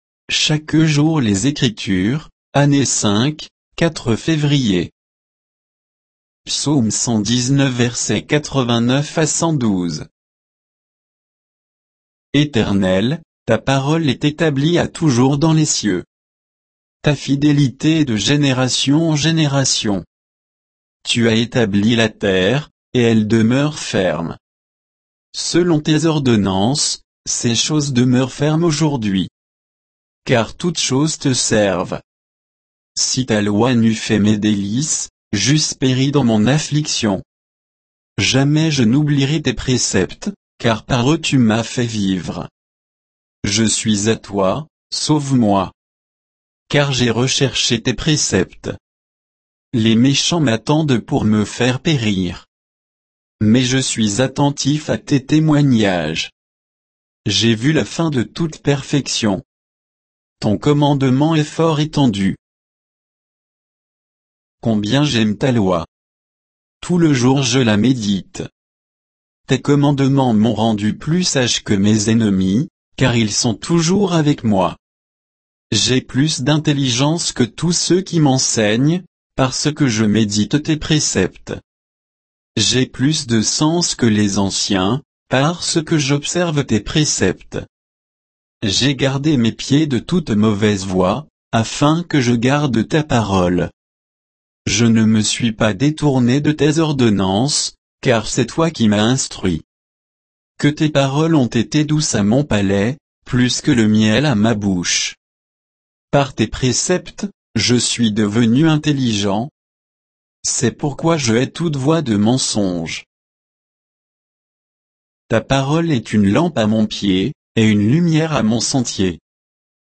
Méditation quoditienne de Chaque jour les Écritures sur Psaume 119